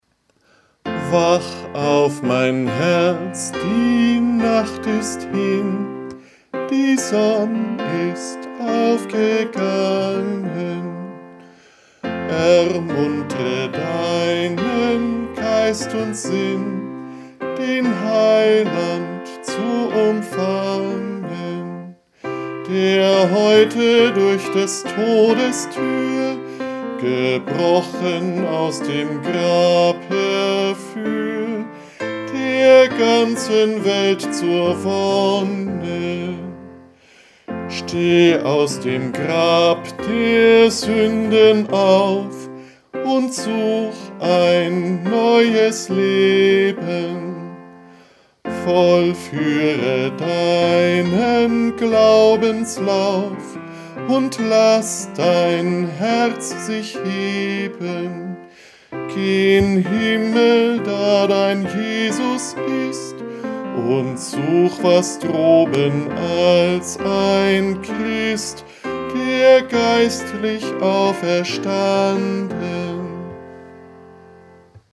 Eingesungen: Liedvortrag von Kantor